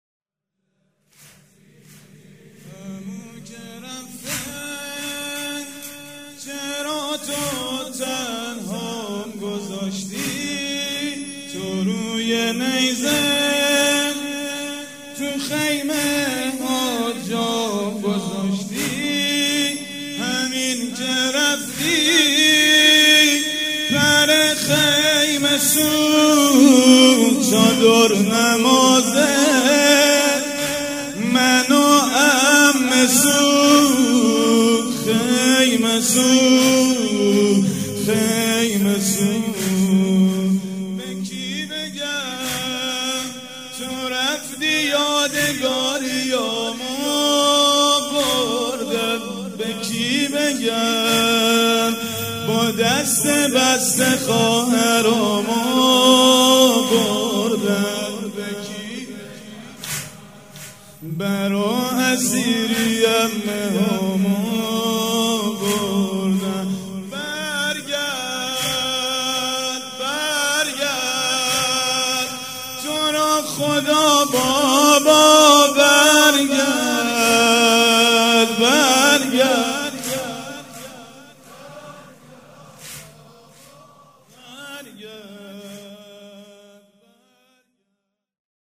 شب سوم محرم - هیئت ریحانه الحسین(ع) - مهر 95